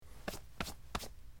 Cloth, Wipe
Brushing Off Clothes With Hands, X6